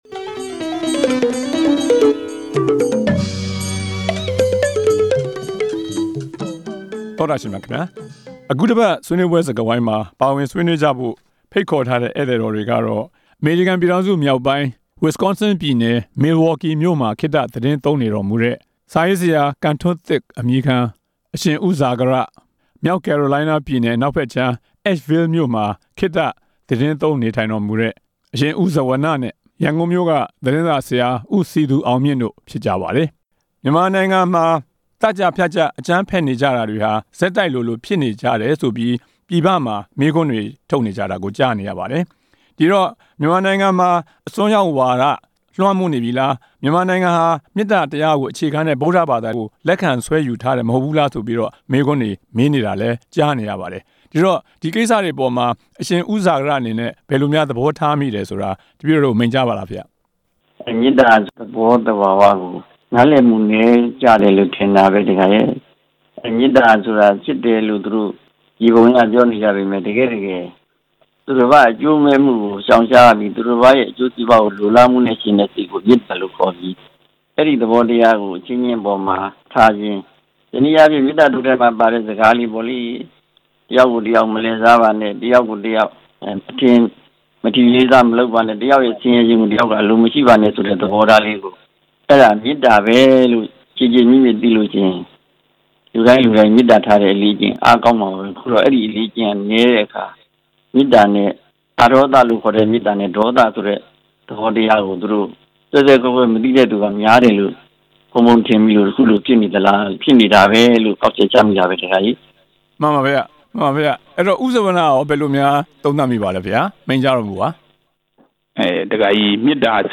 ရဟန်းတော် နှစ်ပါး၊ မီဒီယာသမားတစ်ဦးတို့နဲ့အတူ
ဆွေးနွေးပွဲ